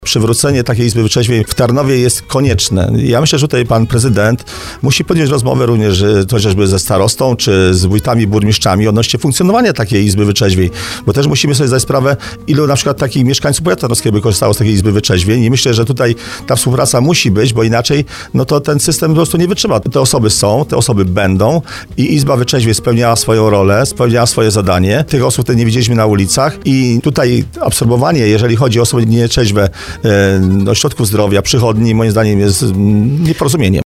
O tym pisaliśmy TUTAJ Wicestarosta Roman Łucarz, który był gościem programu Słowo za Słowo podkreśla, że problem nietrzeźwych w przestrzeni publicznej wymaga systemowego rozwiązania, a przywrócenie izby wytrzeźwień mogłoby poprawić bezpieczeństwo i porządek w mieście.